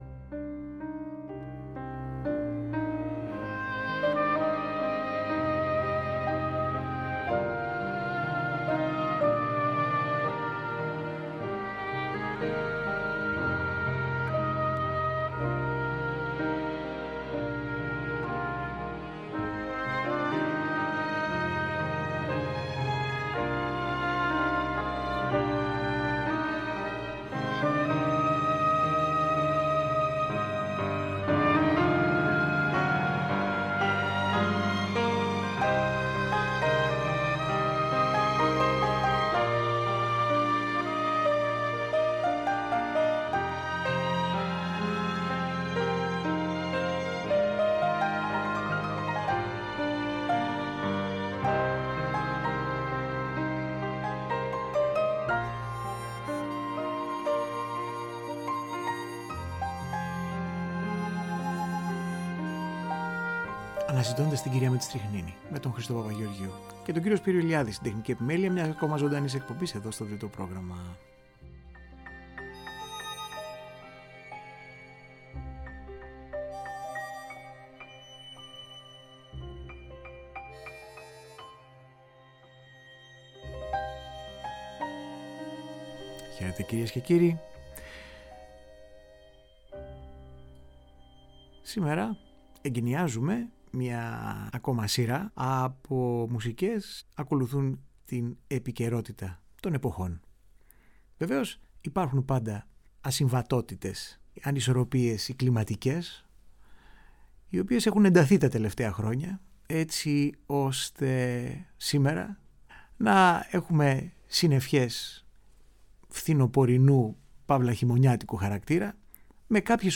Οι Μουσικές για τις καλύτερες ανοιξιάτικες ταινίες όλων των εποχών.